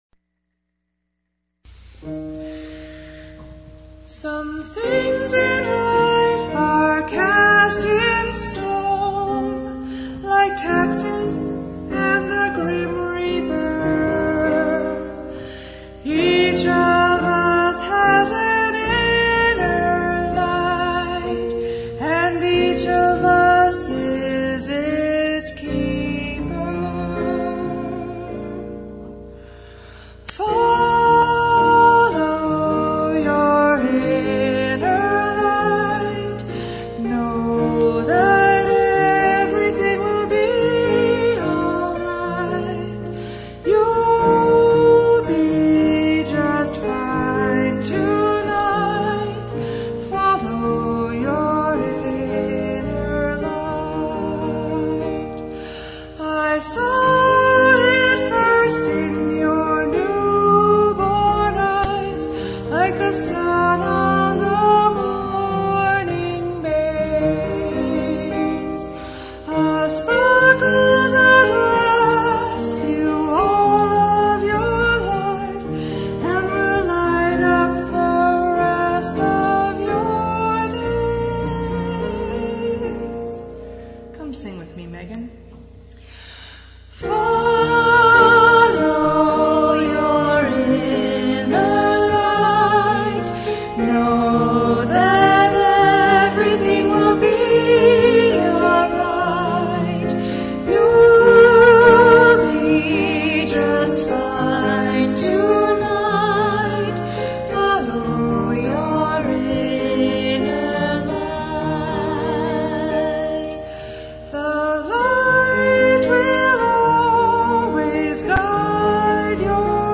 Here you can hear samples from both the original sample recording and from the premier perrformance at the Waldo Theater.